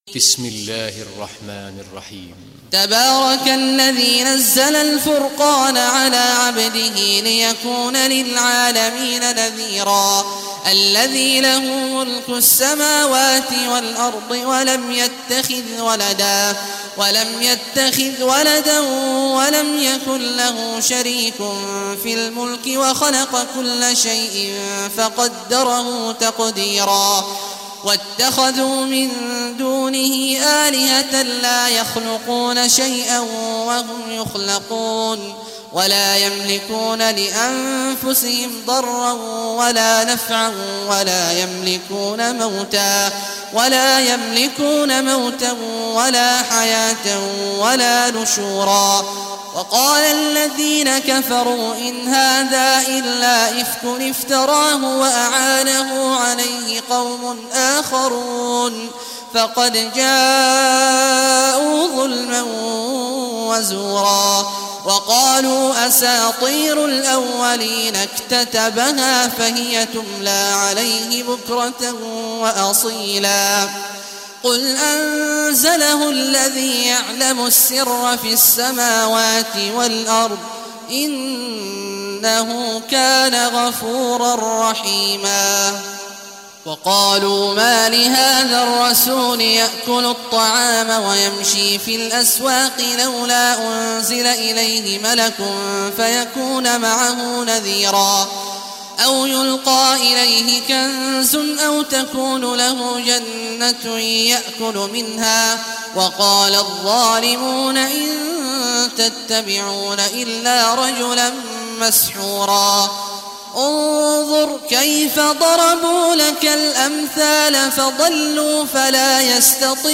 Surah Furqan Recitation by Sheikh Abdullah Juhany
Surah Furqan, listen or play online mp3 tilawat / recitation in Arabic in the beautiful voice of Sheikh Abdullah Awad al Juhany.